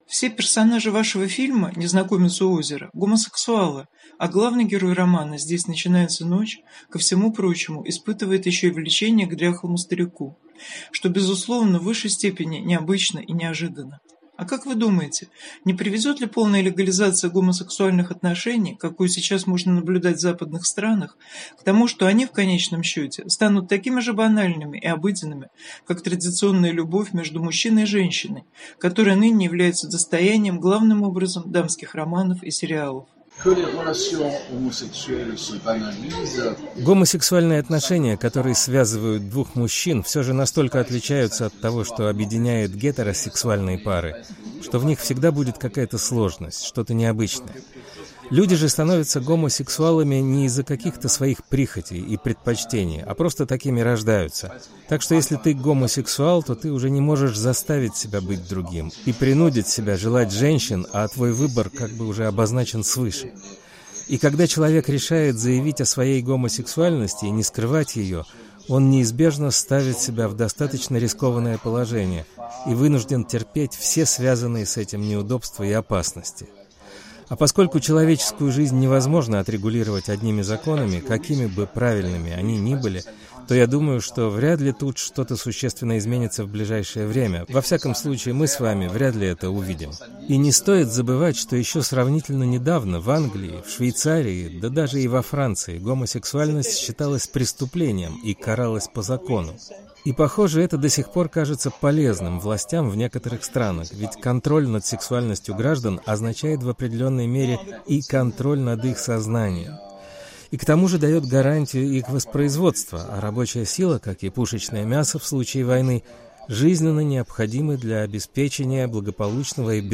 Разговор с Аленом Гироди в программе "Культурный дневник"